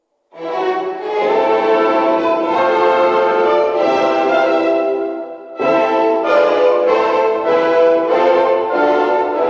The first phrase is a platitude: nothing good can come of it and nothing does. Throughout, the virtuoso passagework is straight out of the catalogue.